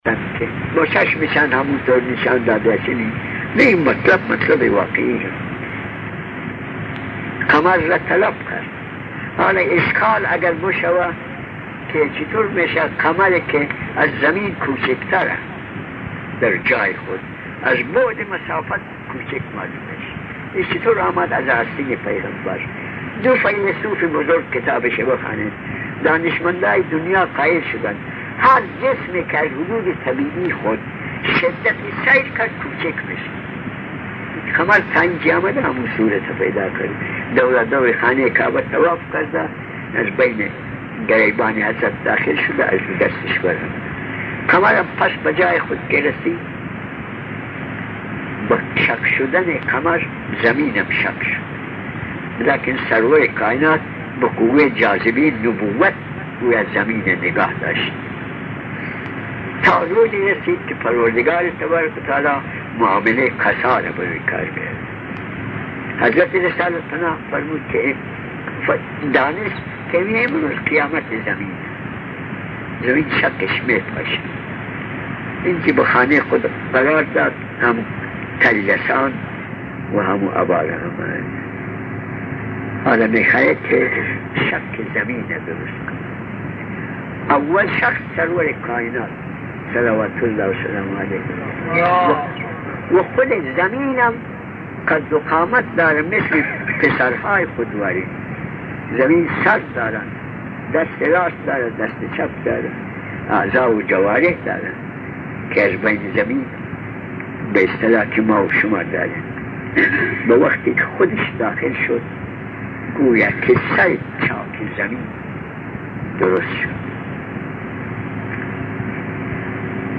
سخنرانی جلسه هشتم